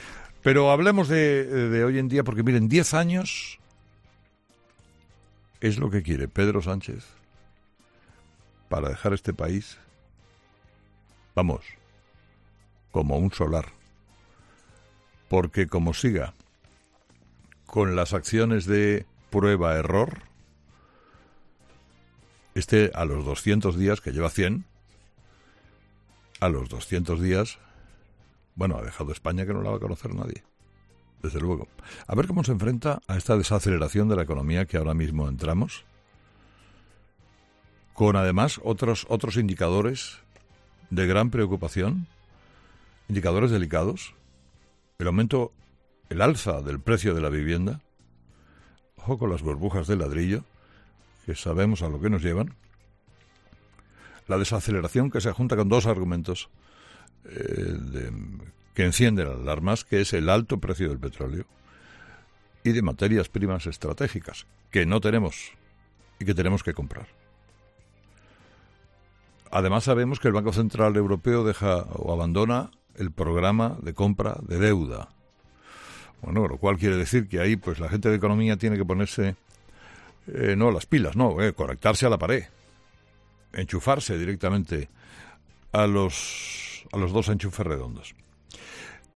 En su monólogo de este lunes, Carlos Herrera también ha mostrado su preocupación por lo que puede pasar cuando pasen doscientos días.